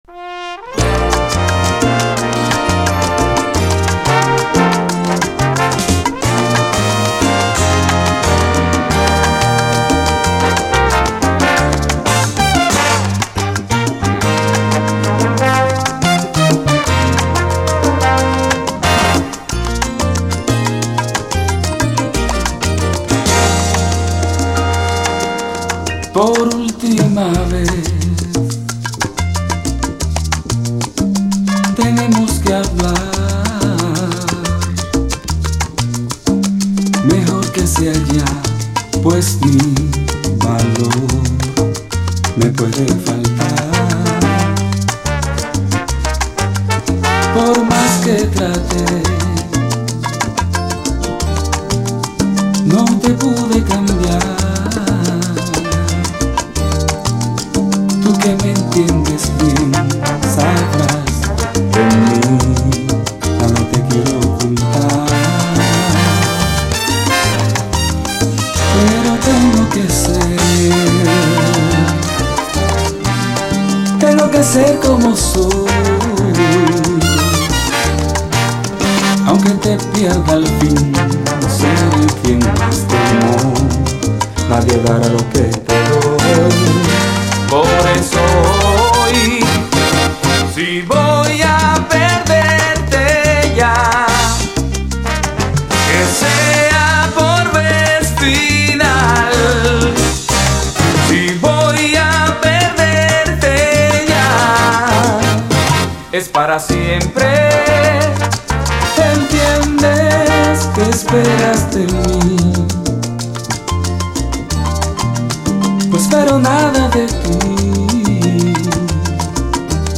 SALSA, LATIN